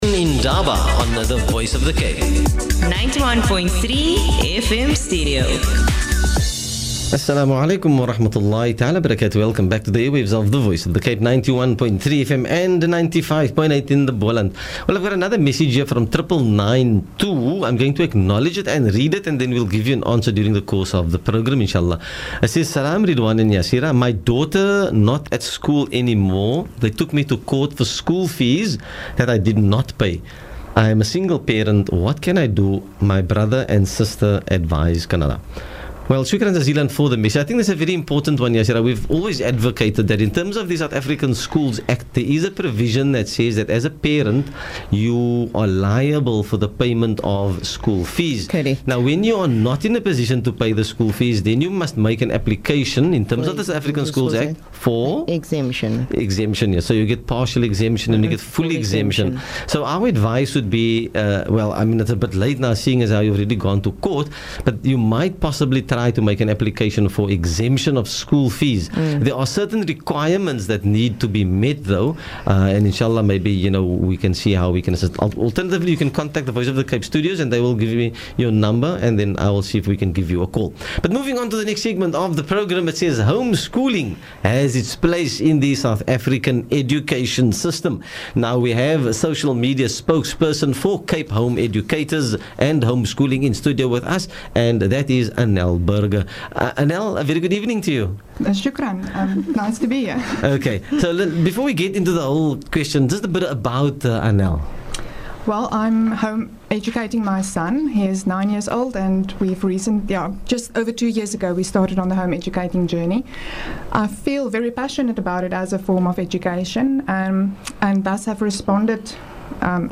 Radio interview on home education